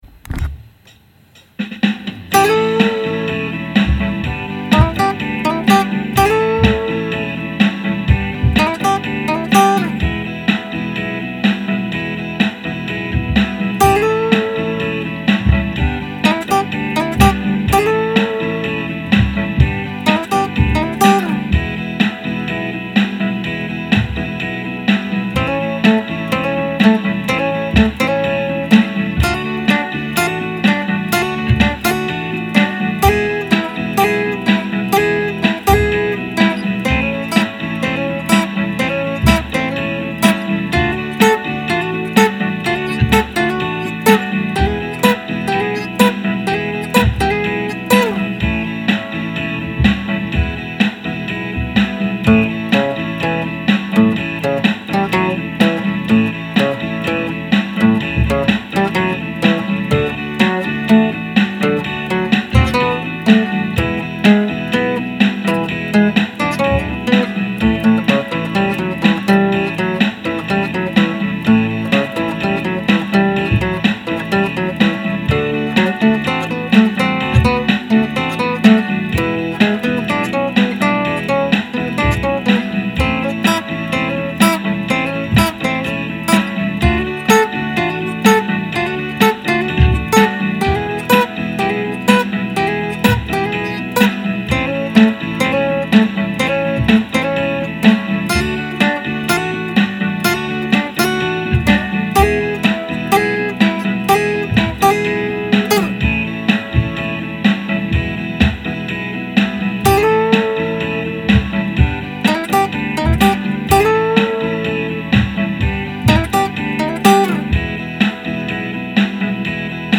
(Rock)